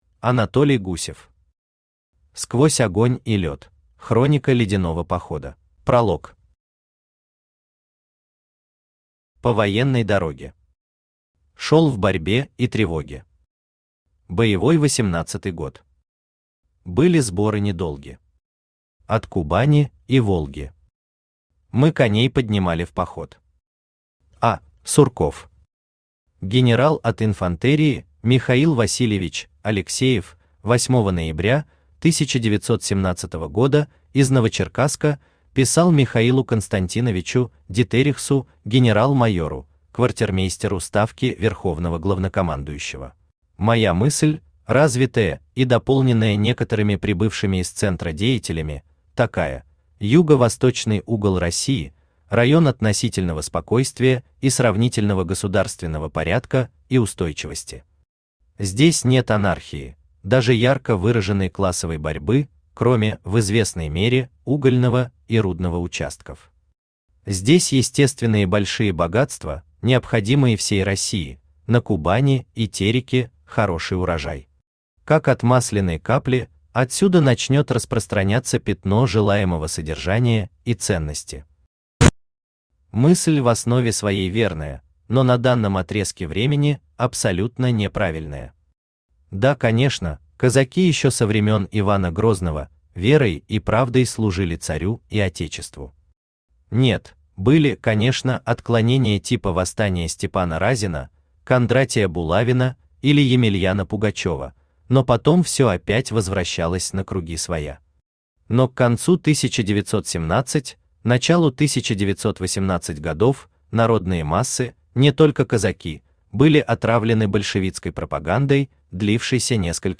Аудиокнига Сквозь огонь и лёд. Хроника «Ледяного похода» | Библиотека аудиокниг
Хроника «Ледяного похода» Автор Анатолий Алексеевич Гусев Читает аудиокнигу Авточтец ЛитРес.